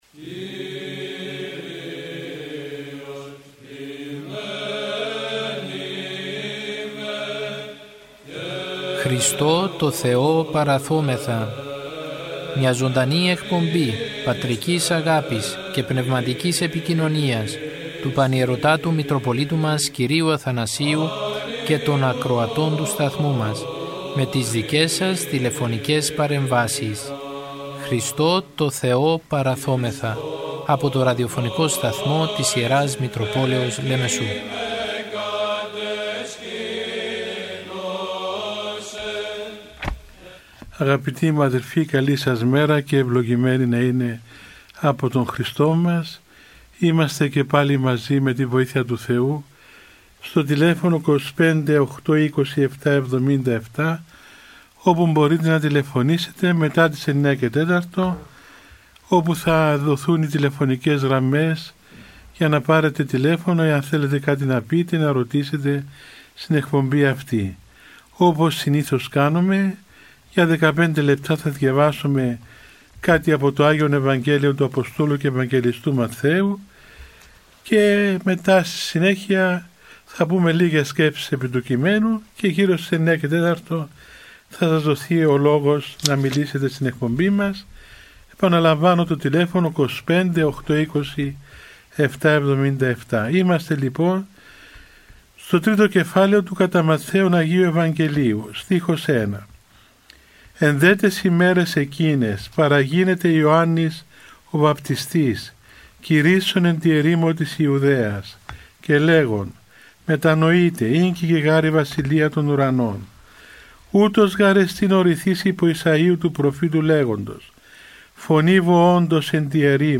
Ο Πανιερώτατος Μητροπολίτης Λεμεσού κ. Αθανάσιος μέσω του ραδιοφωνικού σταθμού της Μητροπόλεως του καθημερινά επικοινωνεί με το ποίμνιο με τηλεφωνικές συνδέσεις και απευθύνει παρηγορητικό λόγο για την δοκιμασία που περνάει ο Ορθόδοξος λαός για την πανδημία με κλειστές εκκλησίες και απαγορεύσεις.